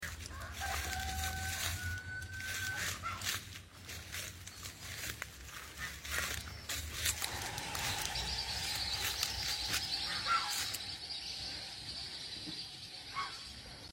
Sound of wild ducks living sound effects free download
Sound of wild ducks living in a pònd